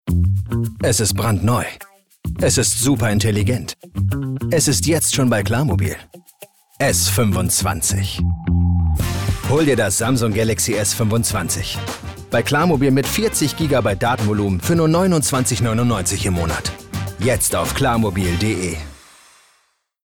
sehr variabel, dunkel, sonor, souverän, plakativ, markant
Mittel minus (25-45)
Funkspot | "klarmobil"
Commercial (Werbung)